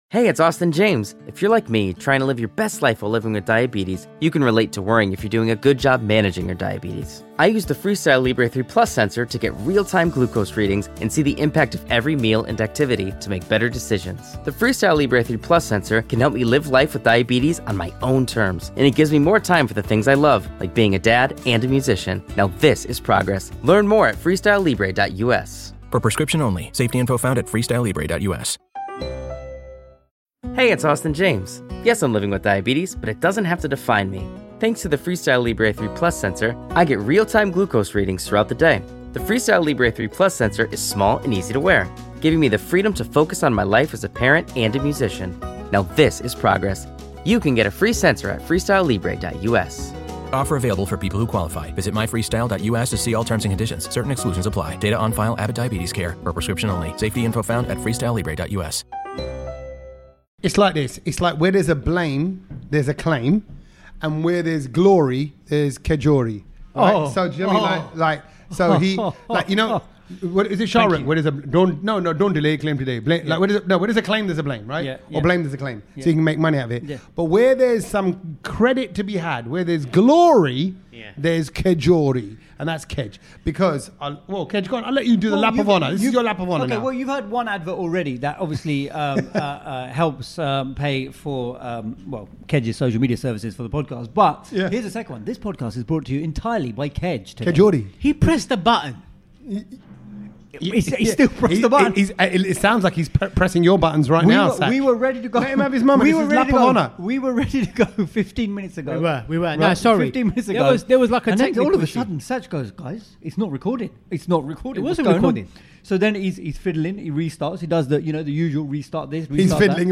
Headliner Embed Embed code See more options Share Facebook X Subscribe We’re coming at you from Vijays Virasat restaurant in Newbury Park (East London), and there’s plenty to catch up on! National anthems getting remixed, Priyanka Chopra picking her favourite food and India getting its very own Disneyland!